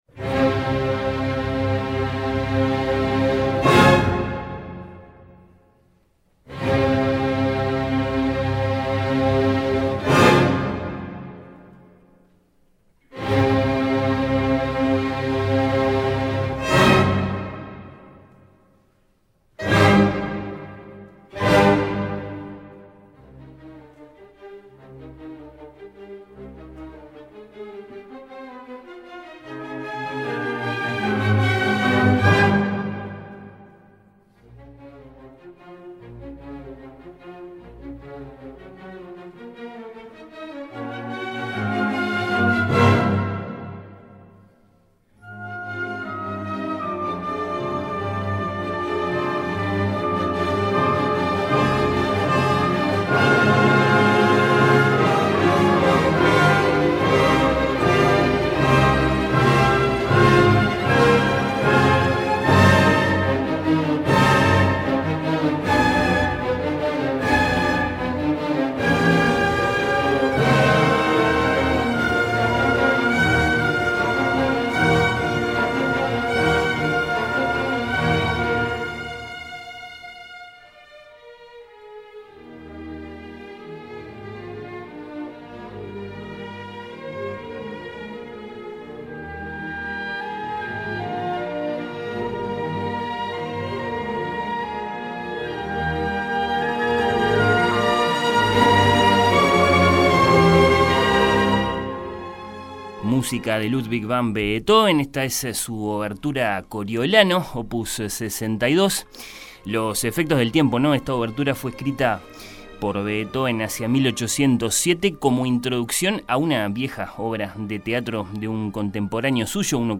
Oír con los ojos anticipó el concierto con fragmentos musicales y comentarios.